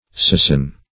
Search Result for " sasin" : The Collaborative International Dictionary of English v.0.48: Sasin \Sa"sin\, n. (Zool.)